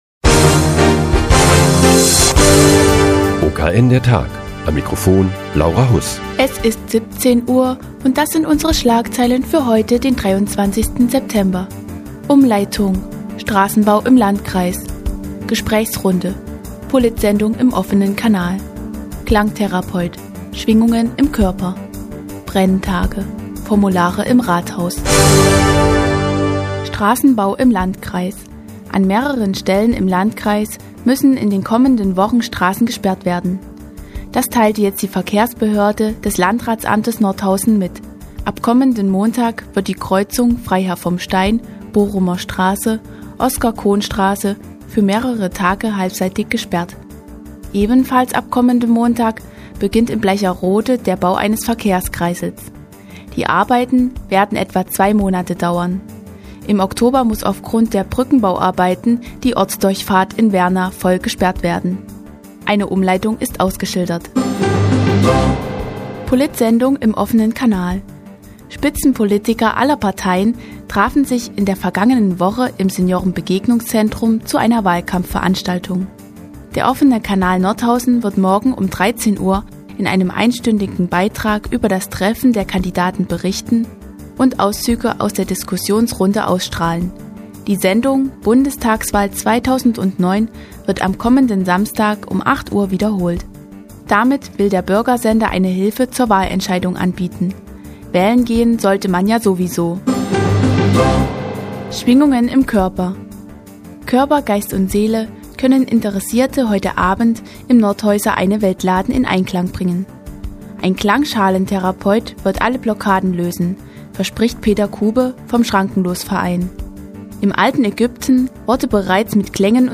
Die tägliche Nachrichtensendung des OKN ist nun auch in der nnz zu hören. Heute geht es um eine politische Gesprächsrunde, einen Klangtherapeuten und die Herbst-Brenntage.